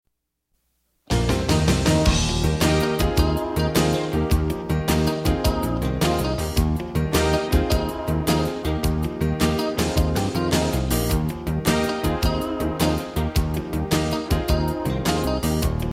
Habitat Song Lyrics and Soundclip for Children